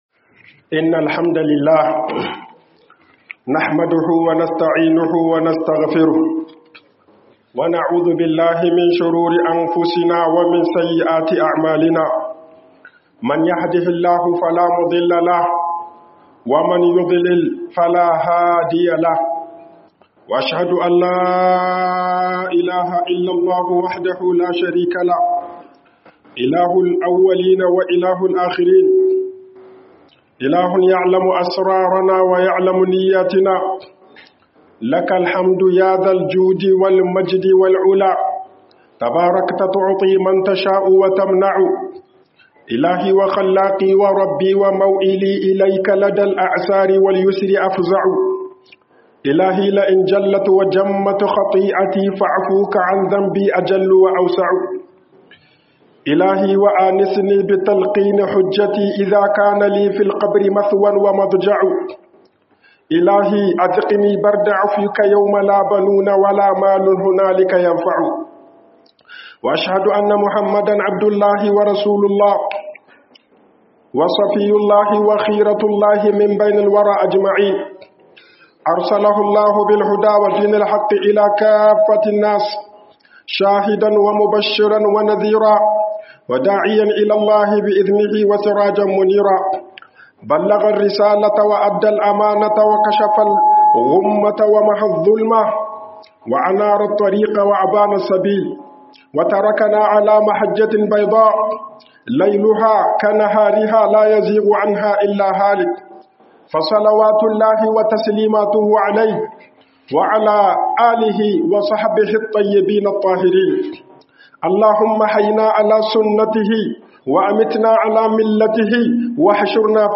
HUƊUBOBIN JUMA'A